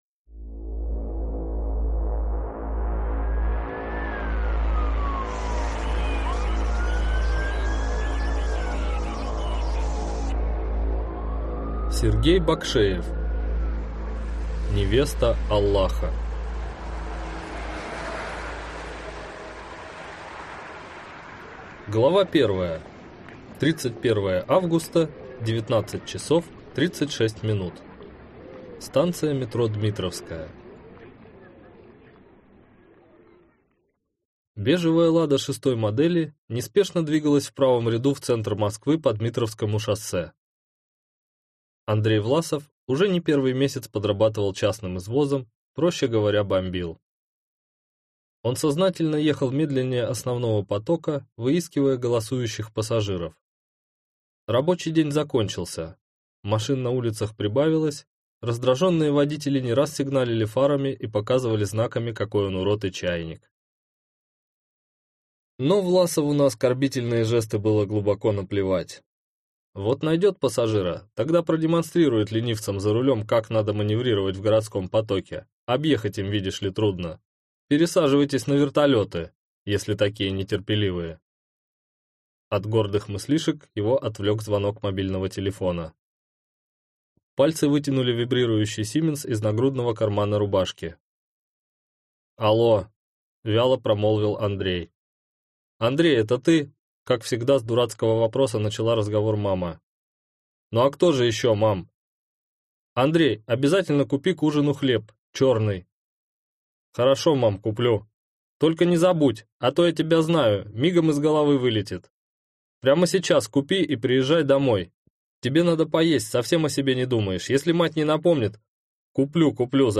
Аудиокнига Невеста Аллаха | Библиотека аудиокниг